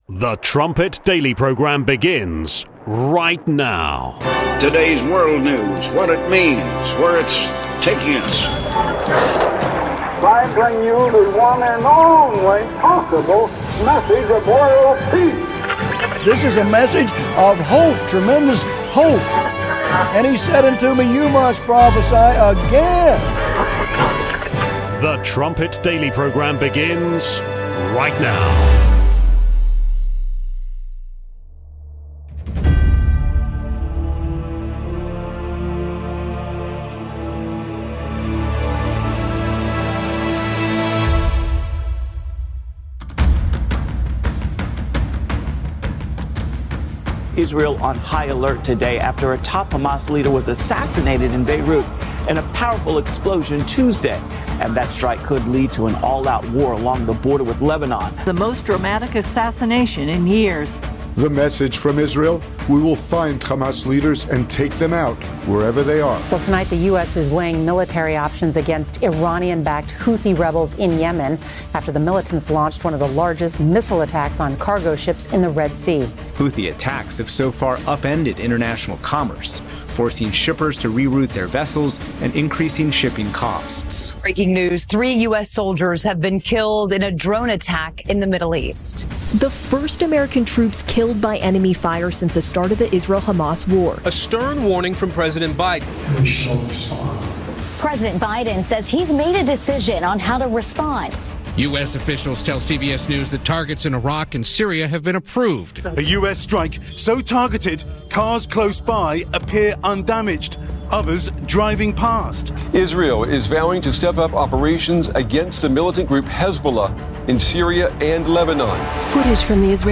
2024 has been an action-packed year, as evidenced by this montage of major events covered on the Trumpet Daily this year.